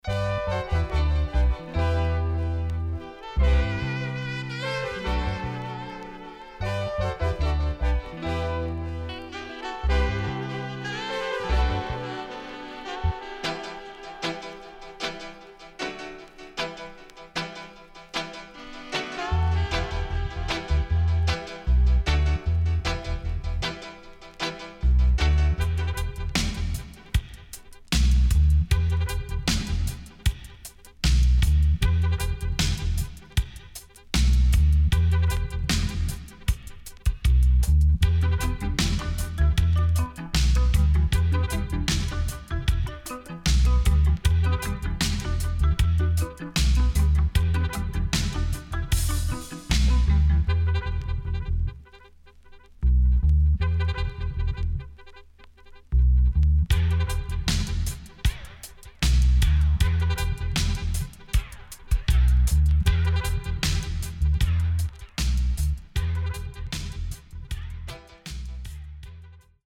SIDE A:所々チリノイズがあり、少しプチノイズ入ります。
SIDE B:所々チリノイズがあり、少しプチノイズ入ります。